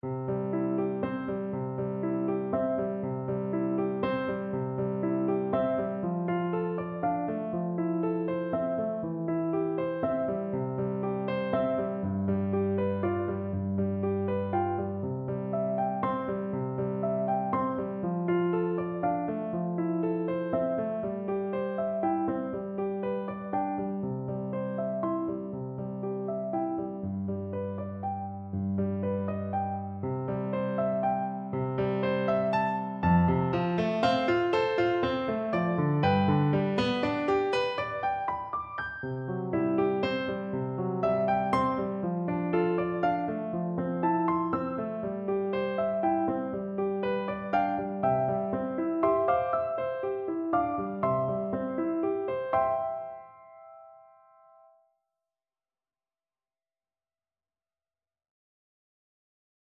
Andantino =c.120 (View more music marked Andantino)
6/8 (View more 6/8 Music)
Traditional (View more Traditional Flute Music)
world (View more world Flute Music)